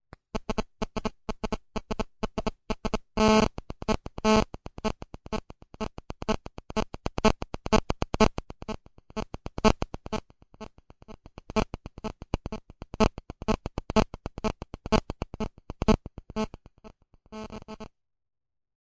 Звук помех от мобильного телефона (фонит рядом с электронным устройством)